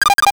NOTIFICATION_8bit_13_mono.wav